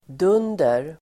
Uttal: [d'un:der]